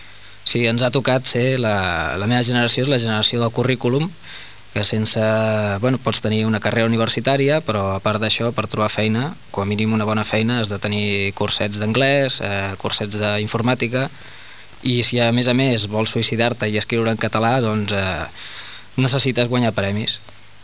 EntrevistaMostra Sonora - 20 seg.